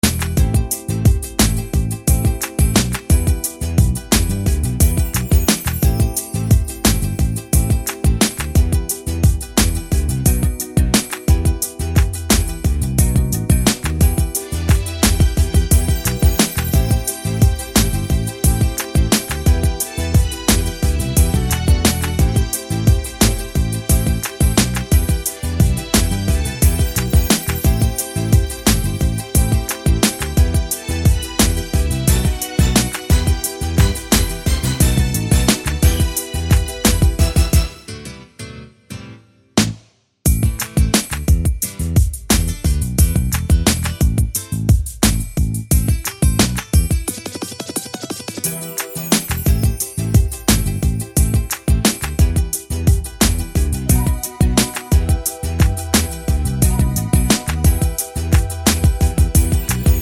no Backing Vocals R'n'B / Hip Hop 4:23 Buy £1.50